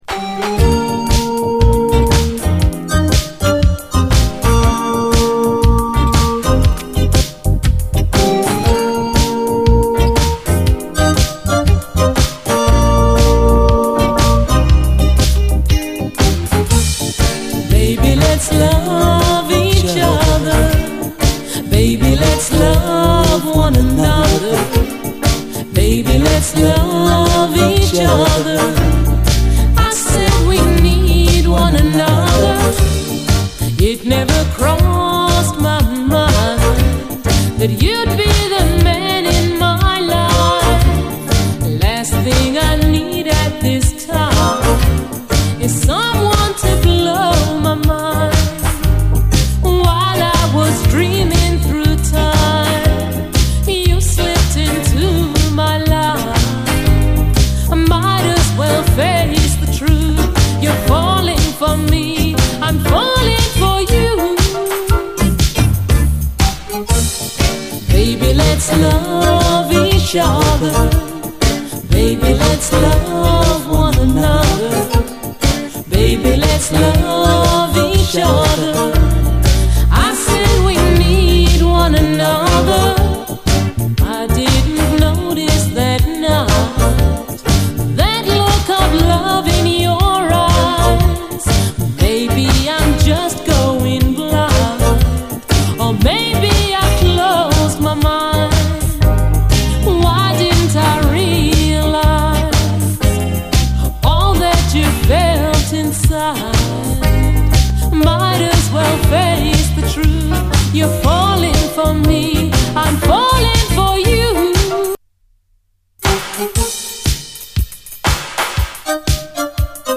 REGGAE
UKラヴァーズ・クラシック！ゆったりとシャッフルするリズム、胸キュン・メロディーのラヴリー・チューン！